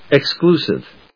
音節ex・clu・sive 発音記号・読み方
/eksklúːsɪv(米国英語), ɪˈksklu:sɪv(英国英語)/